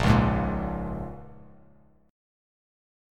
A#m7 chord